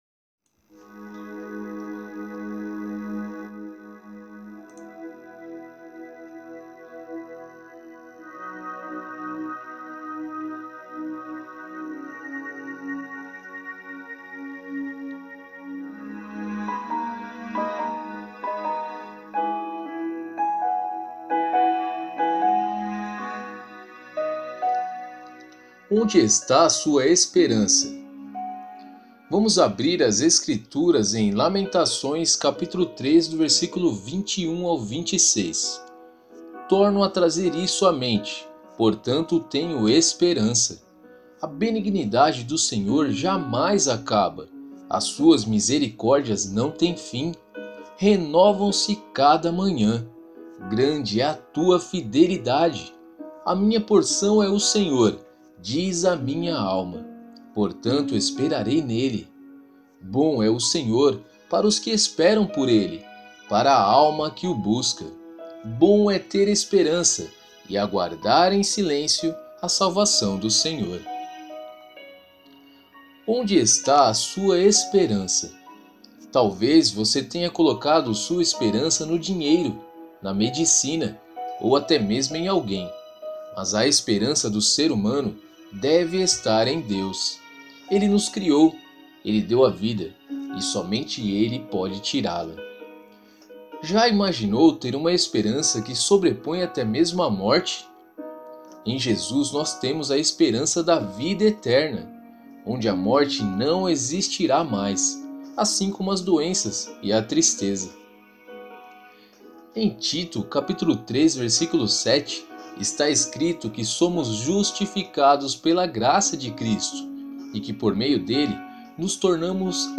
Você também pode ouvir a narração do Alimento Diário!